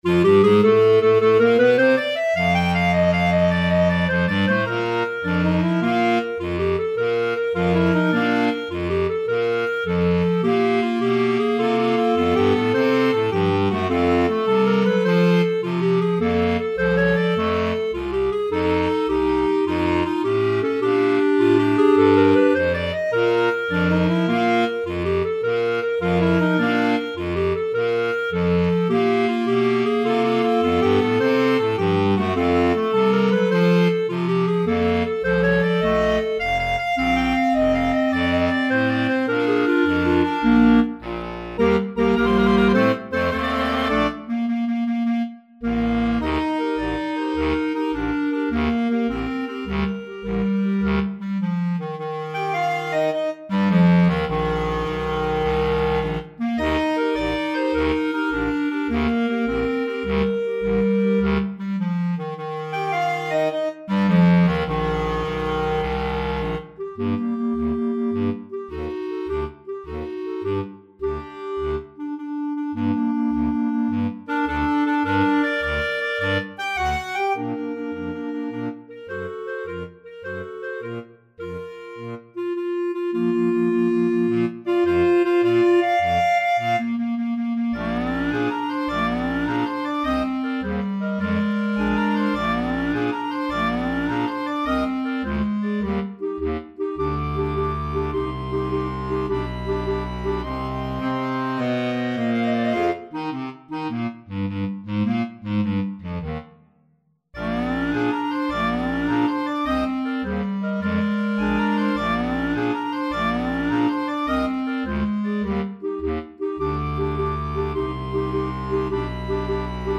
Selectie voor klarinetchoir.mp3